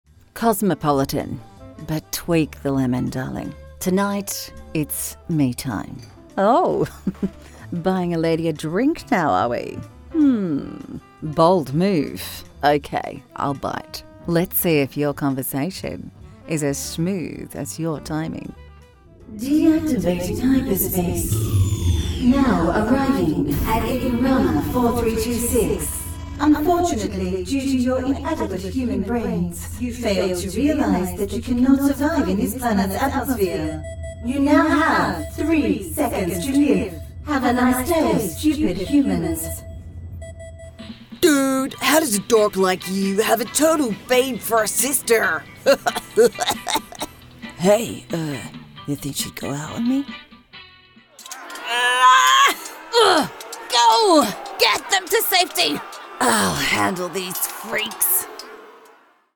Find expert character voices and cartoon voice overs for animation and interactive media.
I can sound like the relatable guy next door.
English (Australian) Adult (30-50) | Older Sound (50+)